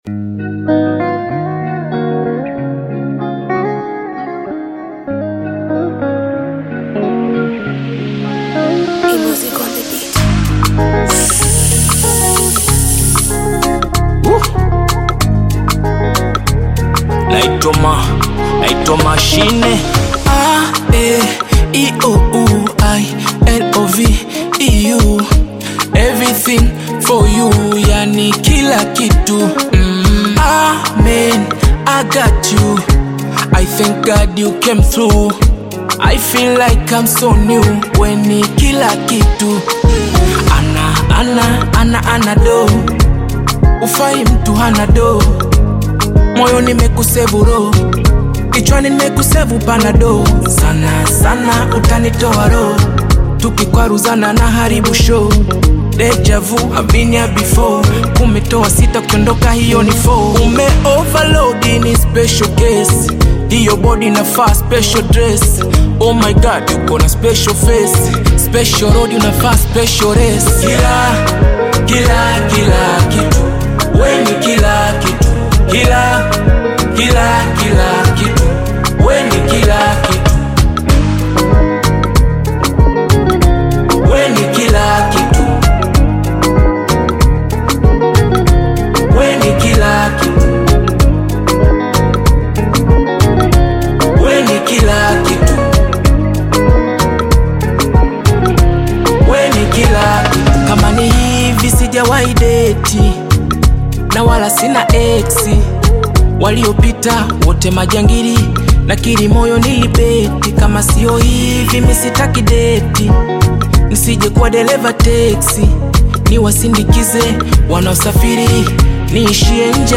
Bongo Flava music track
Tanzanian Bongo Flava artist and singer